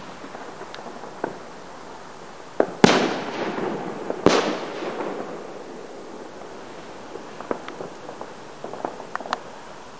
爆炸 " 火灾
描述：使用Audacity放大了遥远的烟火声音。
标签： 炮灰 烟花 扩增 烟花 消防
声道立体声